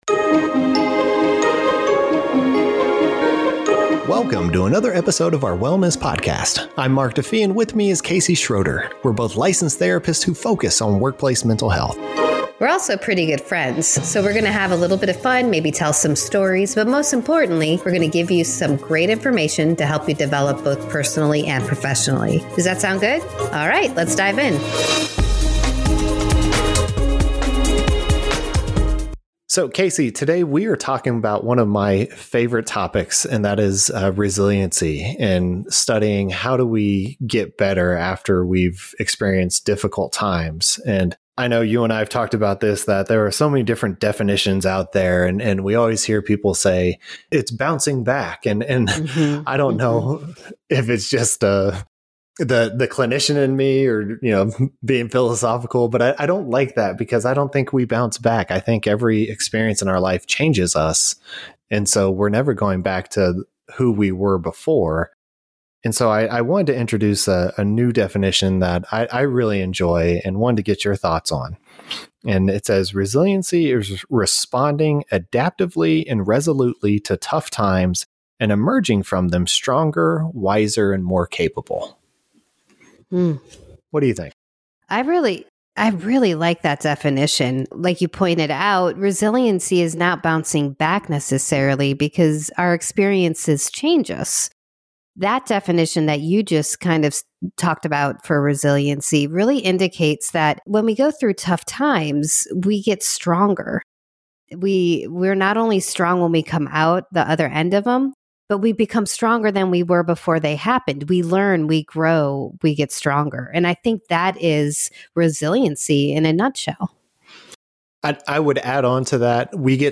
building-your-resilience-podcast-episode.mp3